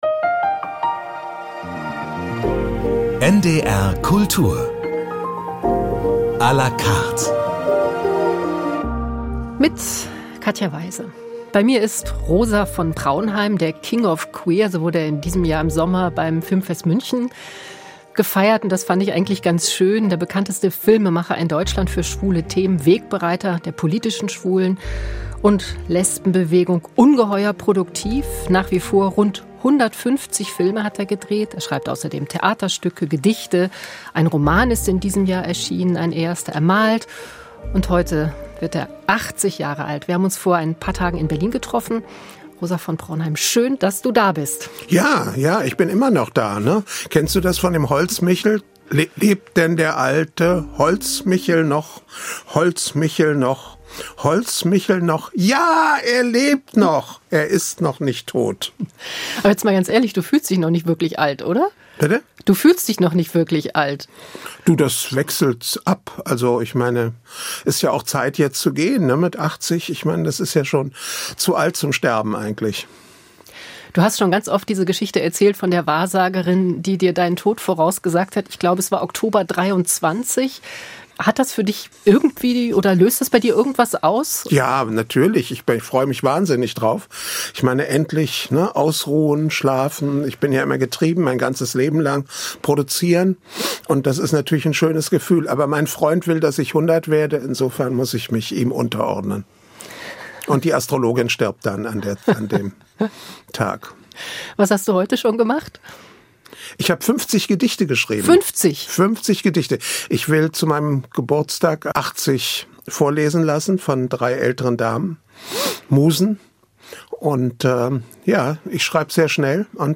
Beschreibung vor 3 Monaten Zum Tod von Rosa von Praunheim im Dezember 2025 ein Gespräch aus dem November 2022 - zum damals 80. Geburtstag des Künstlers.